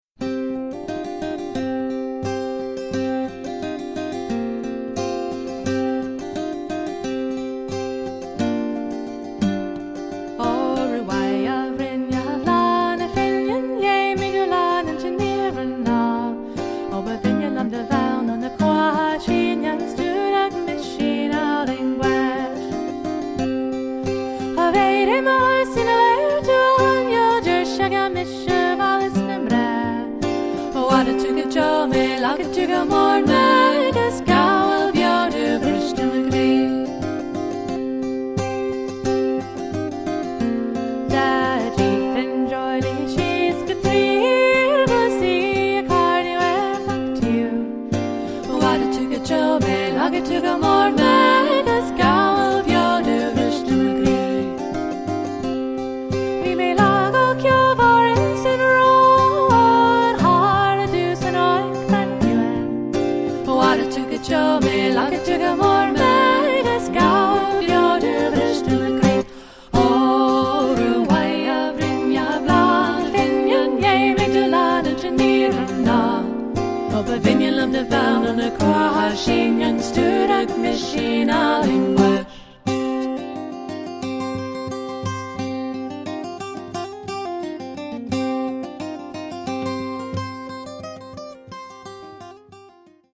This San Francisco Irish Band plays traditional Irish Music with Fiddle, Flute, Guitar, and Vocals.
San Francisco Irish Band style With soothing lullabies, hypnotic ballads, and good old traditional jigs and reels, this Irish band will mesmerize you with their fresh approach to traditional Irish Music. Along with flute, fiddle, and whistle, they deliver sweet harmonies masterfully accompanied by a wonderful guitarist.